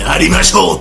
hikakin yarimashiyou Meme Sound Effect